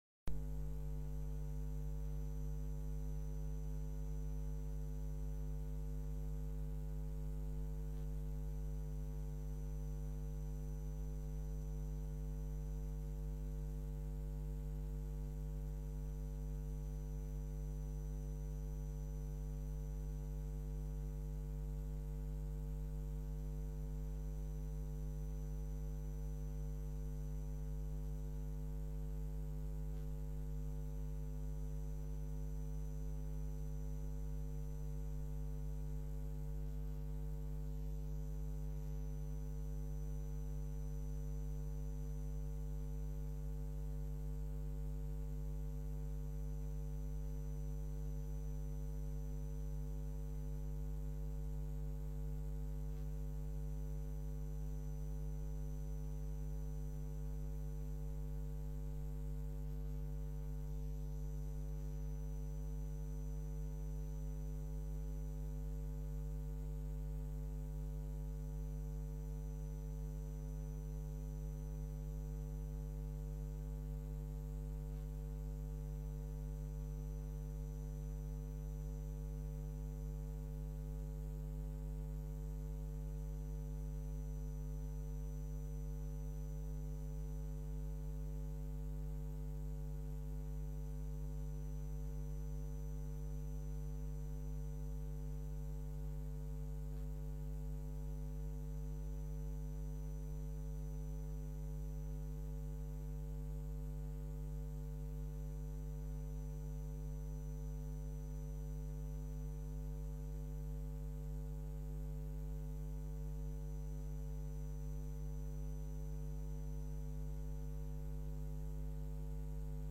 Commissie Ruimte 07 september 2016 19:15:00, Gemeente Goirle
Download de volledige audio van deze vergadering
Locatie: Raadzaal